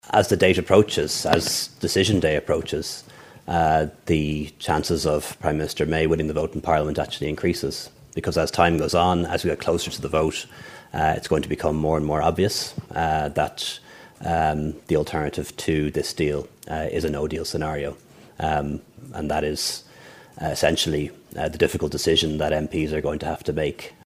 Leo Varadkar says he believes that will come: